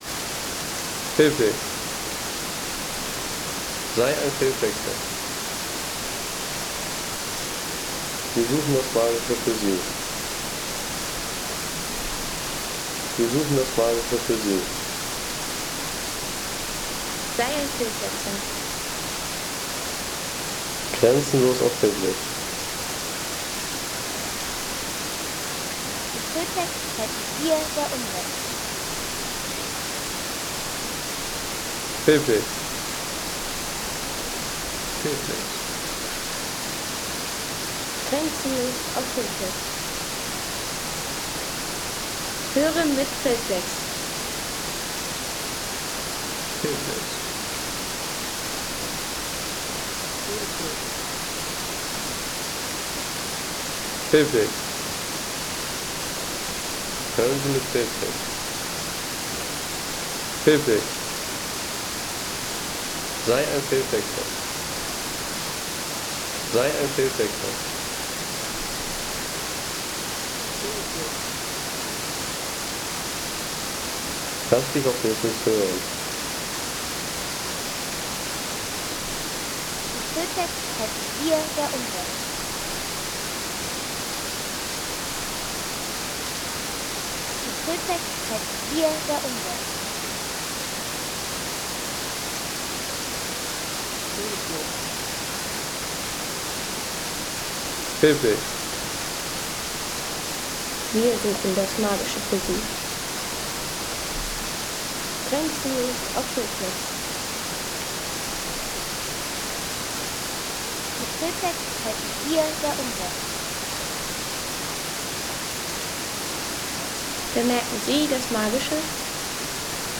Soundshower Gesäuse – 26m Waterfall to Palfau Gorge
Gentle rush of a 26-meter waterfall in Gesäuse National Park – refreshing, calming, and full of natural energy.
Experience the calming roar of a 26-meter waterfall in Gesäuse National Park – nature's sound shower full of clarity and peaceful energy.